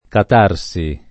[ kat # r S i ]